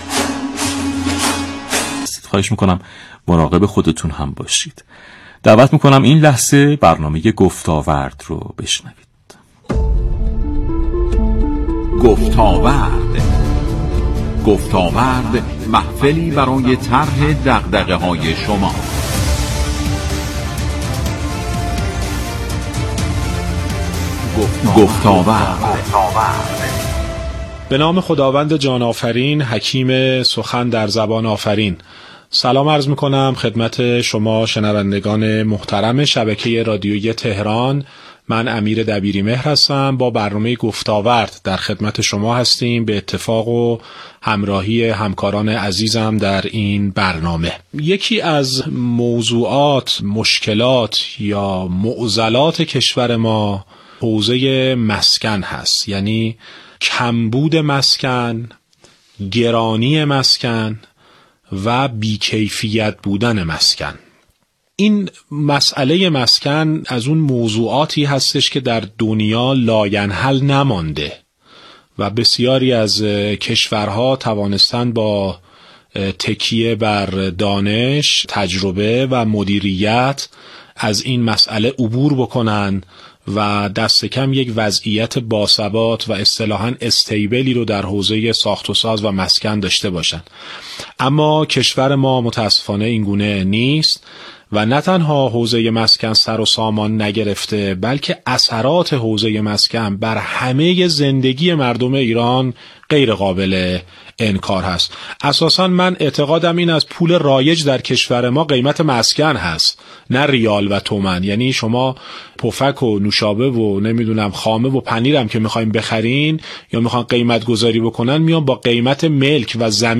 گفتگو با مدافع سرسخت بلند مرتبه سازی برای عبور از بحران مسکن
و اکنون فایل کامل این مصاحبه در ذیل برای دسترسی علاقمندان قرار گرفته است. 📻 رادیو تهران – FM 94